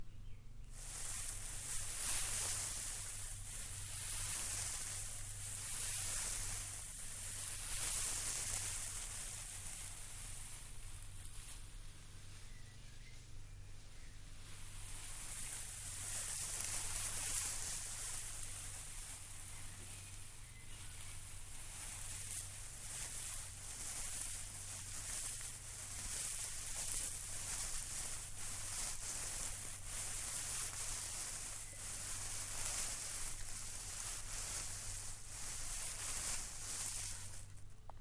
Friction of sawdust
Duration - 38s Environment - Large shed with corrugated roof, many different tools surrounds it. The sawdust is situated in the centre of the shed, as it is extremely large and slightly open you can hear background sounds of neighbors working and the birds also chirping. Description - The sawdust is piled in a large pile on cement ground. It makes a lovely interesting sound that listeners may not know what it is. It is slightly relaxing if you keep listening to it.